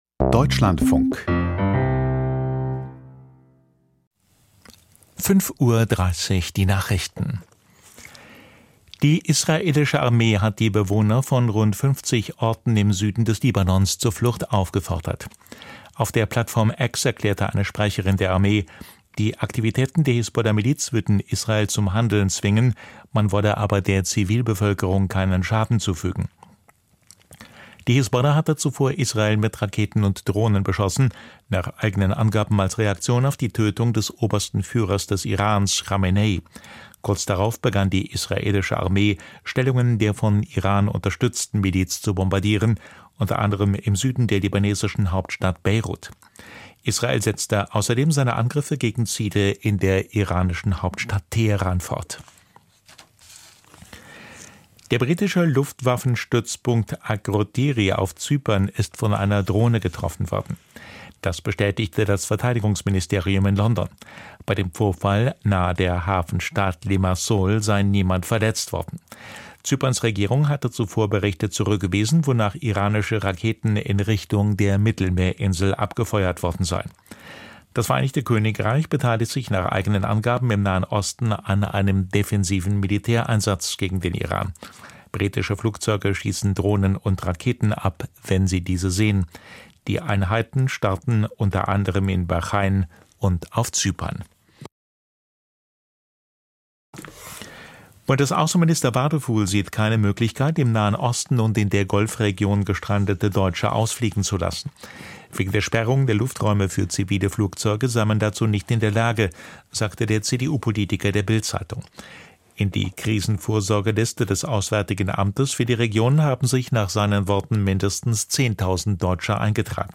Die Nachrichten vom 02.03.2026, 05:30 Uhr
Aus der Deutschlandfunk-Nachrichtenredaktion.